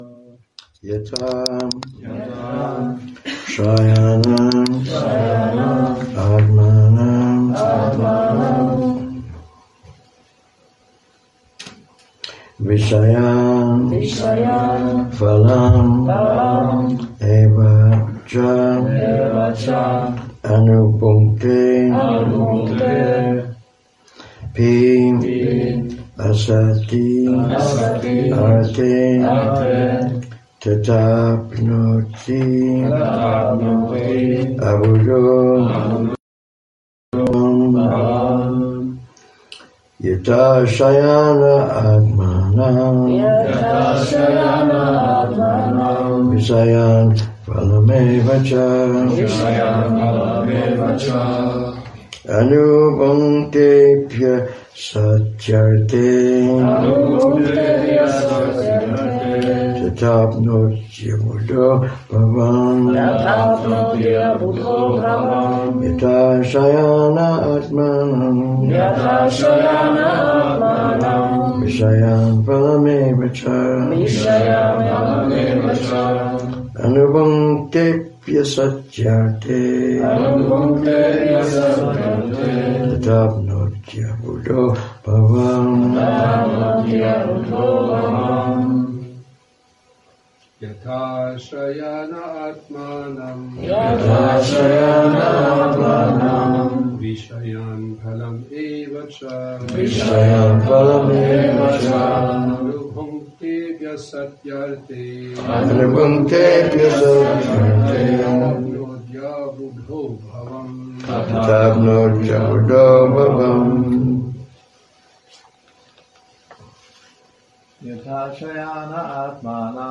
Přednáška SB-10.54.48 – Šrí Šrí Nitái Navadvípačandra mandir